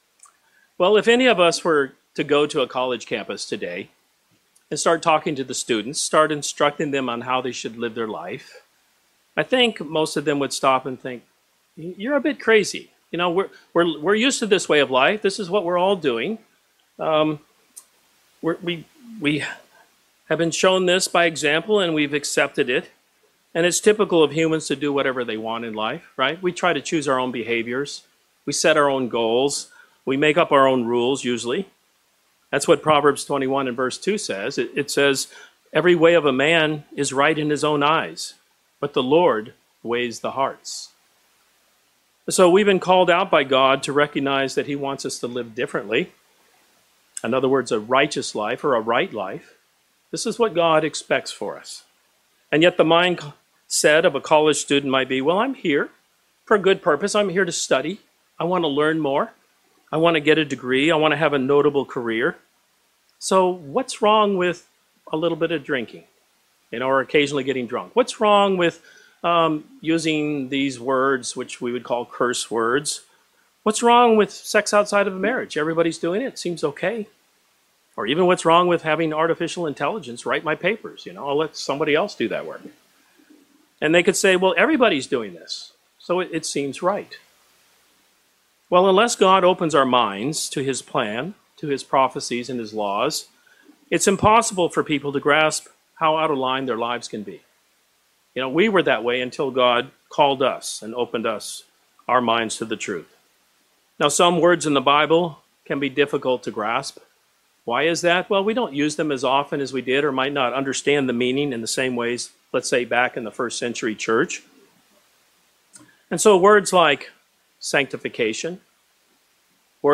God’s love for His children is clearly shown through many of the blessings that He pours out upon us. This sermon discusses two important steps that are necessary for receiving salvation.